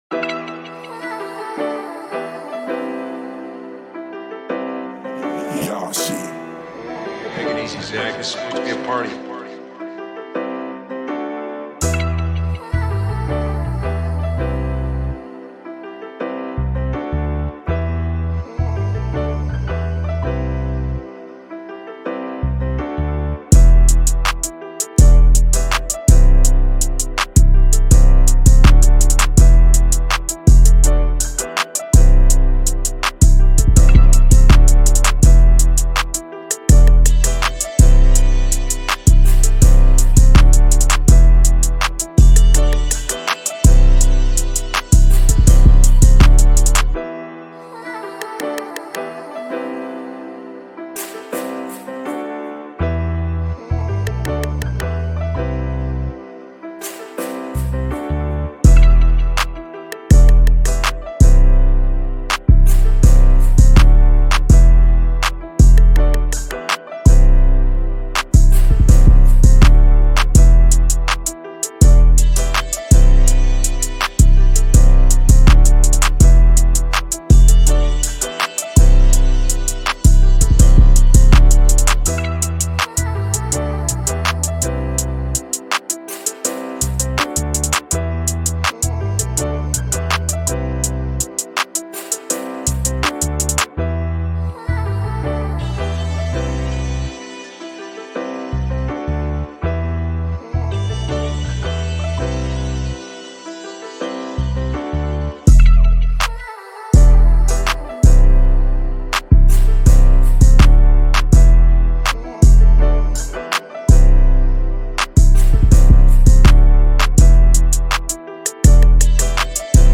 Hip-Hop Instrumental